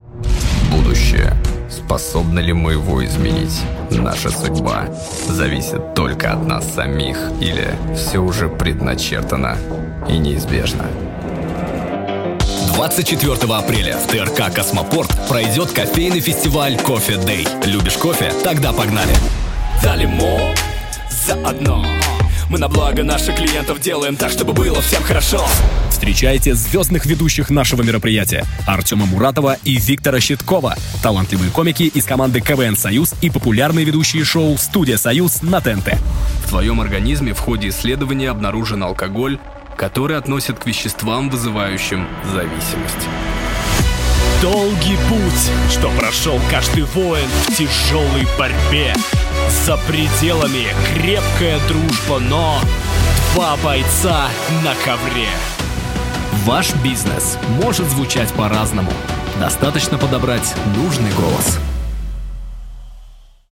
Мужской
Баритон